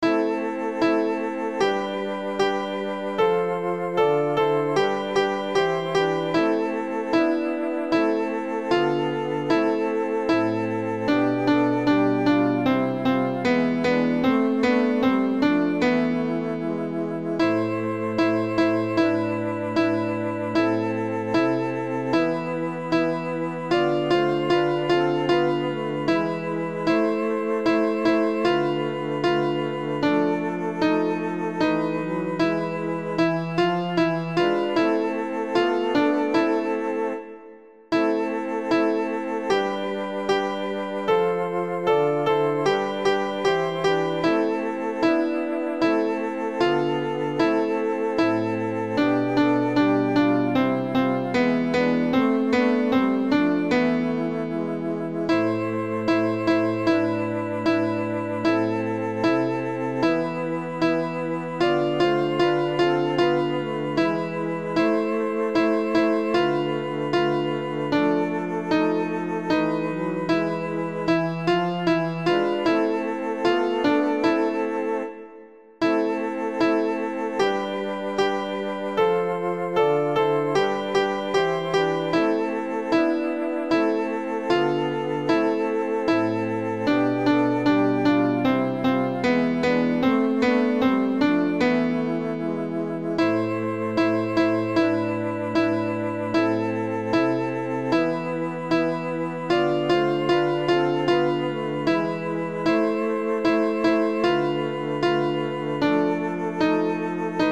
La-priere-du-patre-alto.mp3